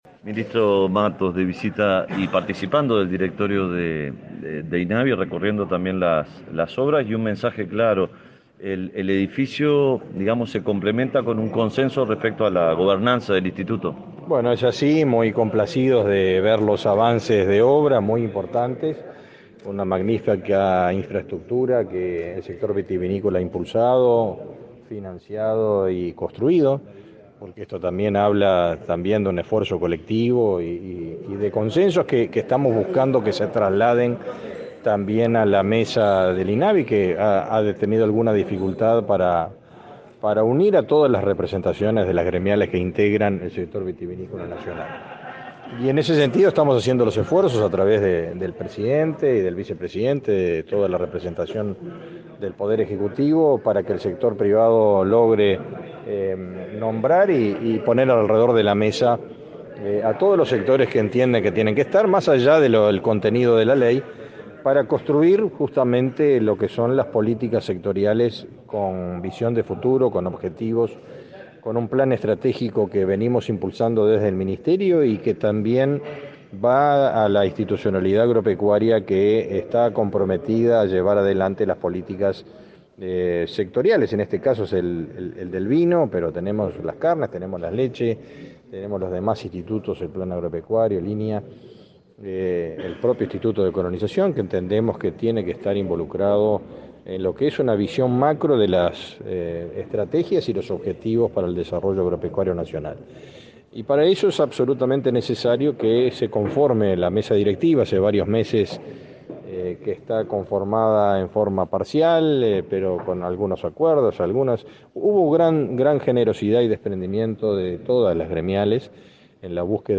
Declaraciones a la prensa del ministro de Ganadería, Fernando Mattos
Declaraciones a la prensa del ministro de Ganadería, Fernando Mattos 22/12/2021 Compartir Facebook X Copiar enlace WhatsApp LinkedIn El ministro de Ganadería, Fernando Mattos, visitó este miércoles 22 la nueva sede del Instituto Nacional de Vitivinicultura (Inavi) y, luego, dialogó con la prensa.